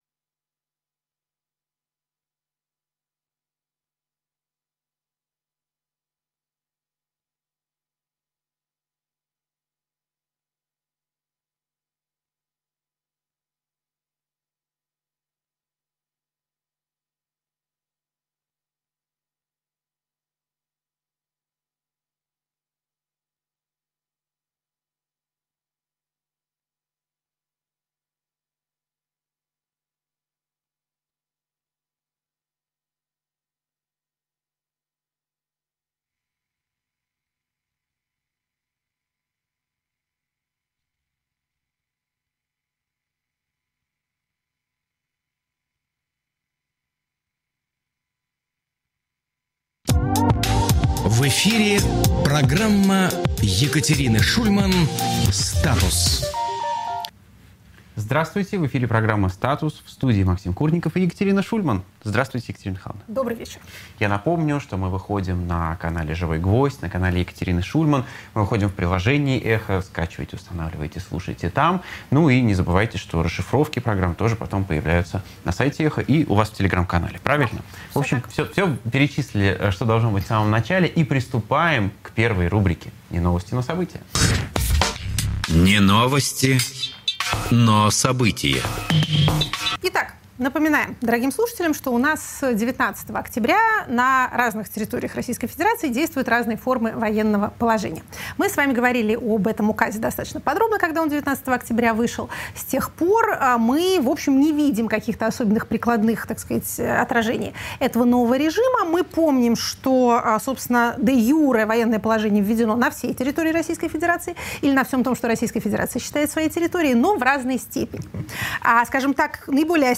Екатерина Шульман политолог